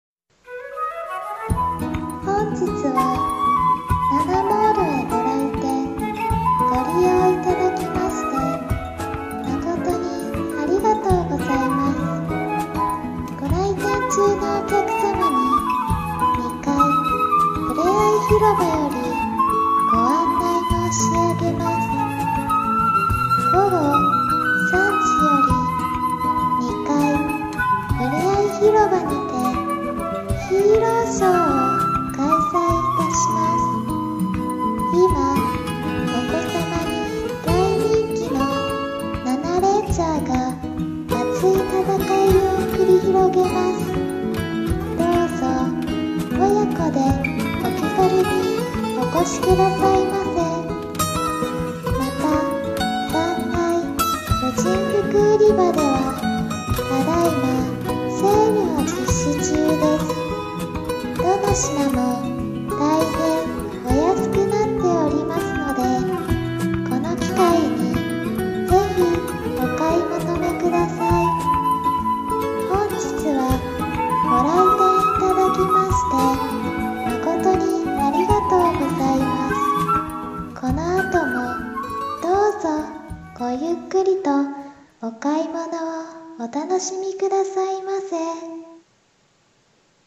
デパートの館内放送アナウンス